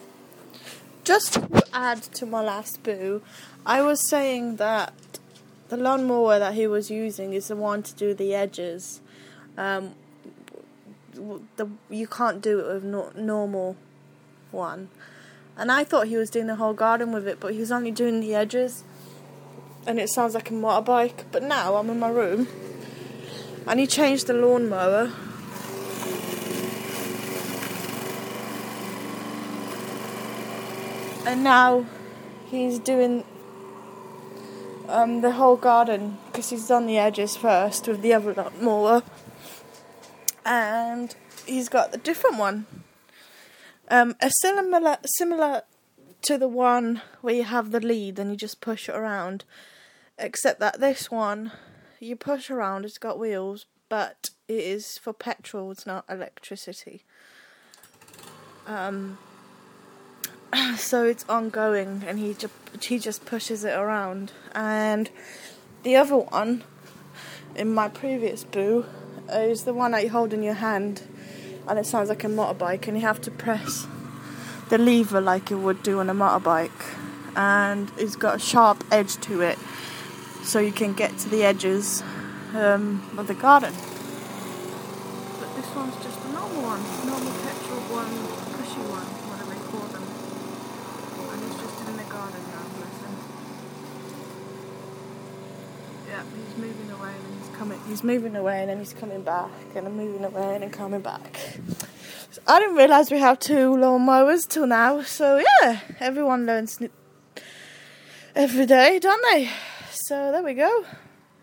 Part 2. Different lawnmower!